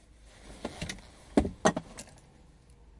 汽车声音（开车、停车、倒车等） " 汽车安全带
描述：内田CRV，内饰，安全带正在紧固。用Zoom H2n记录。